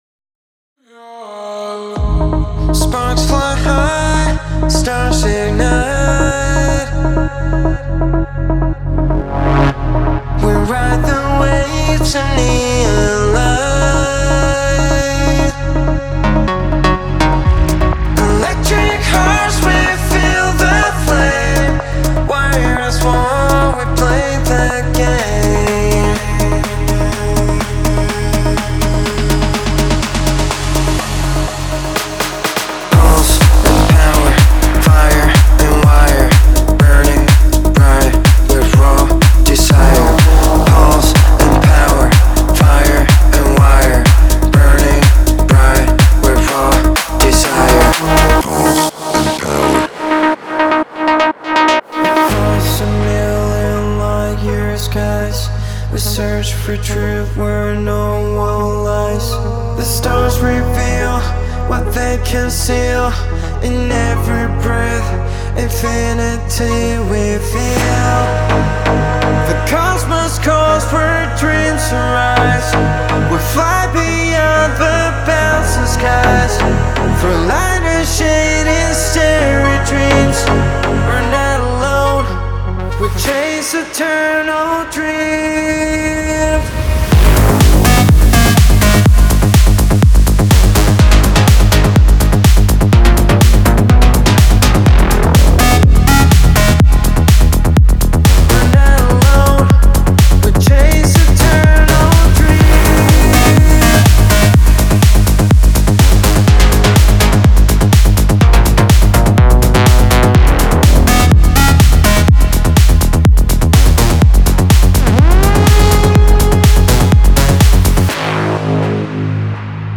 アンダーグラウンド・サウンドの未来へと足を踏み入れましょう。
デモサウンドはコチラ↓
Genre:Melodic Techno
124 BPM